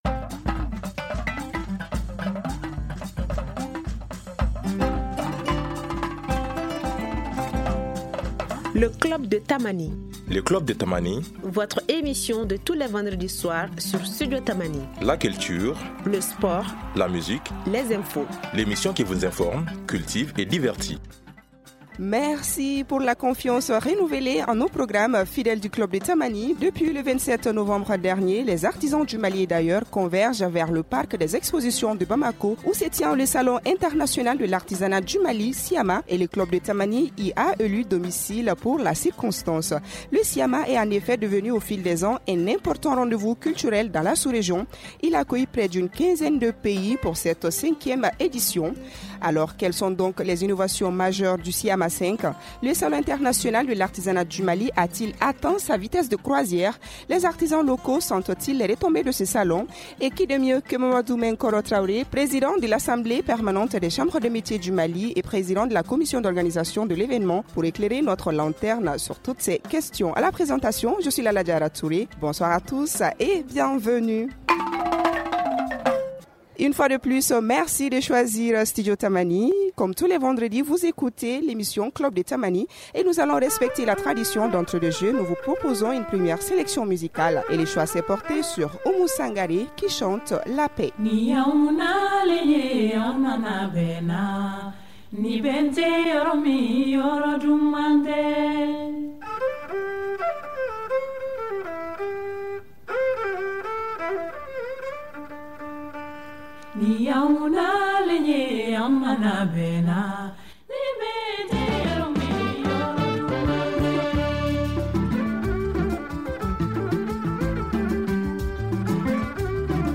Et pour l’occasion, le Club de Tamani y est délocalisé.